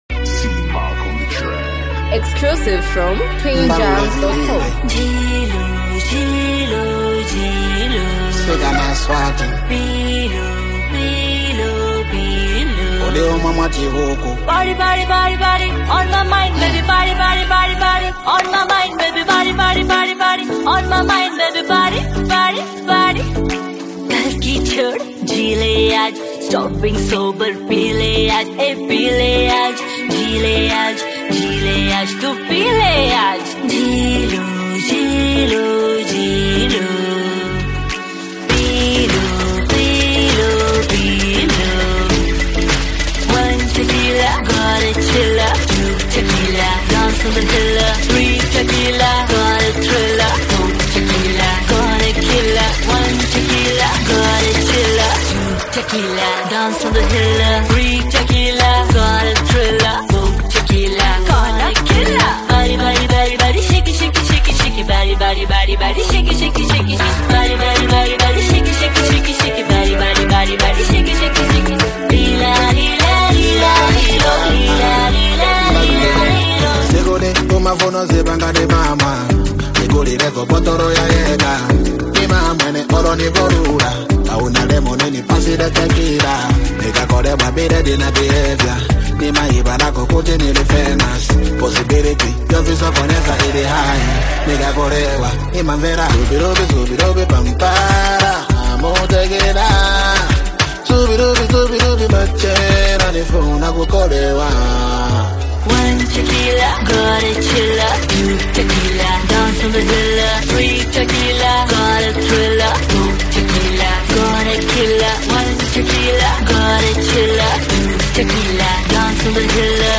upbeat Afro-pop song
he adds his signature Zambian Afro-fusion flavor.